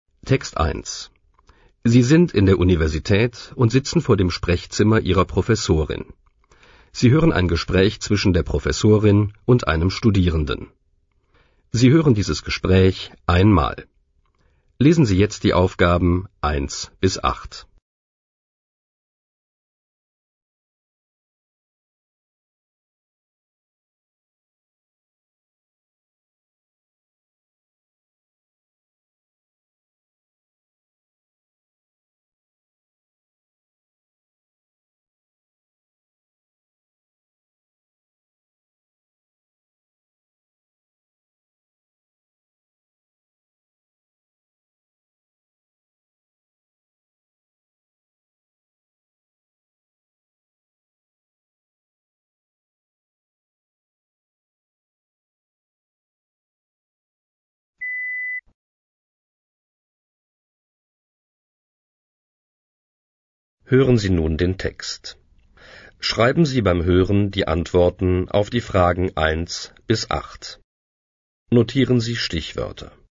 Einleitungstext als MP3-Audio Das Gespräch als MP3-Audio Transkript des Gesprächs Sprechstunde bei der Professorin (0) Weshalb geht der Student zur Professorin in die Sprechstunde?